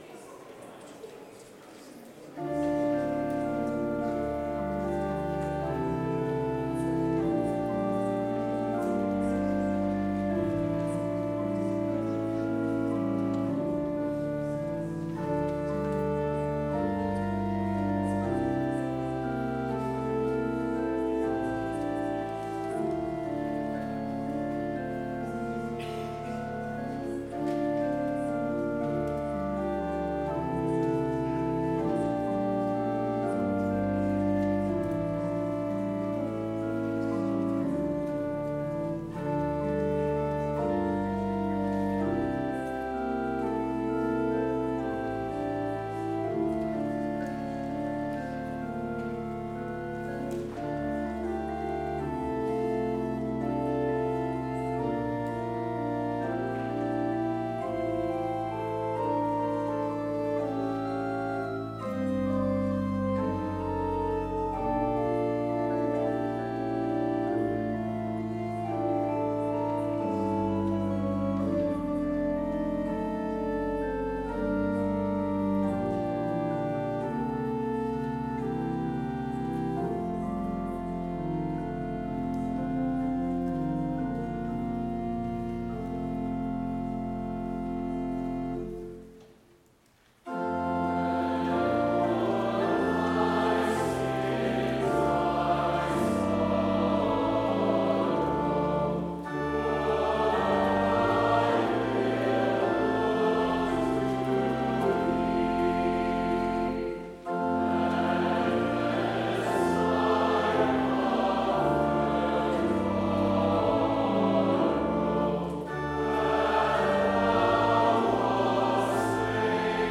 Complete service audio for Lenten Organ Vespers - March 29, 2023
Organ Recital
Canon between soprano and pedal